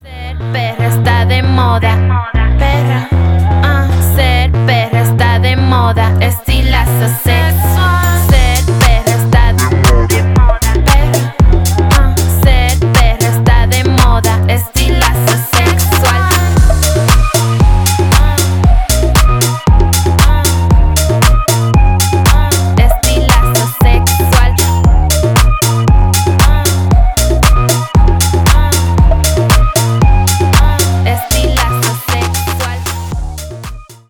громкие , красивые , латинские , поп , клубные